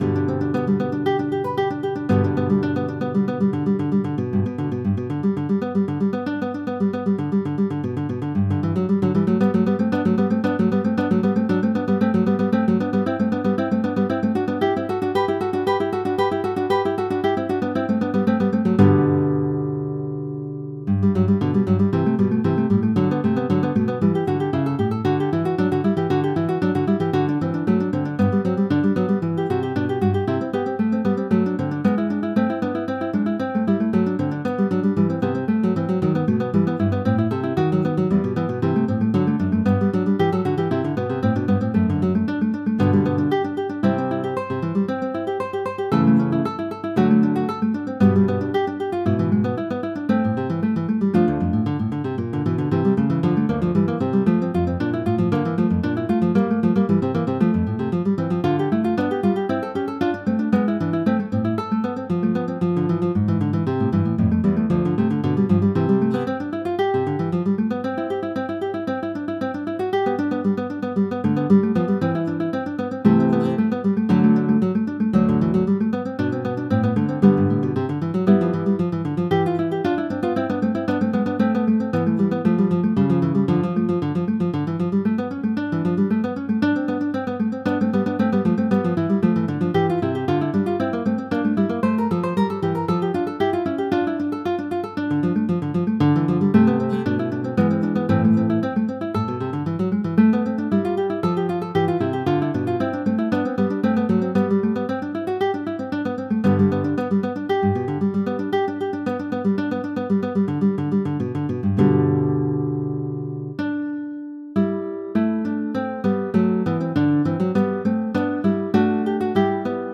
Classical (View more Classical Guitar Duet Music)